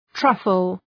Προφορά
{‘trʌfəl}